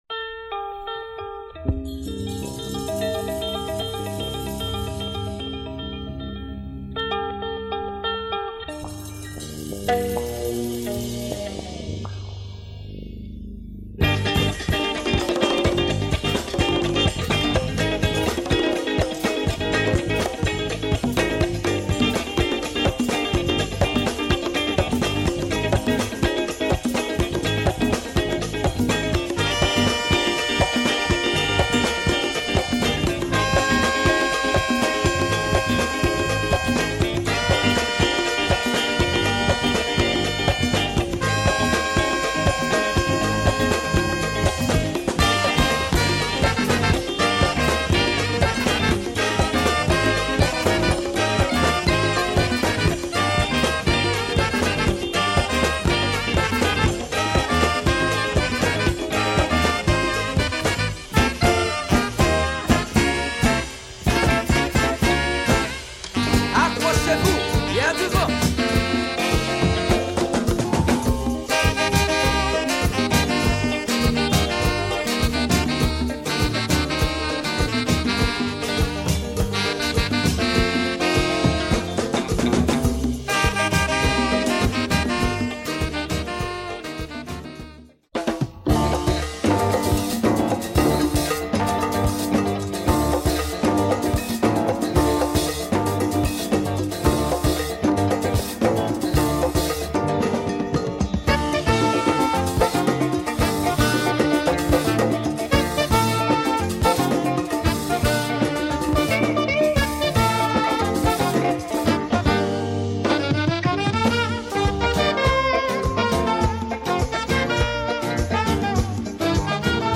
Great copy of that classic Haitian funk album !